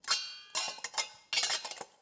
Babushka / audio / sfx / Kitchen / SFX_Cooking_Pot_03_Reverb.wav
SFX_Cooking_Pot_03_Reverb.wav